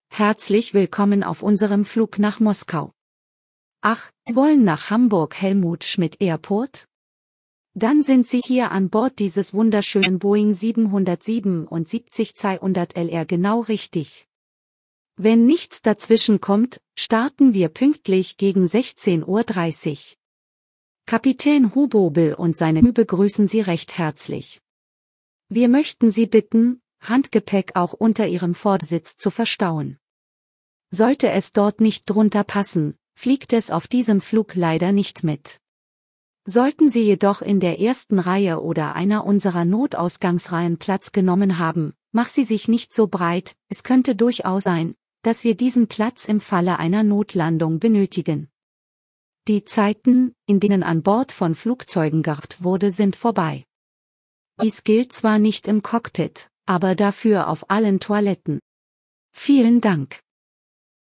BoardingWelcome.ogg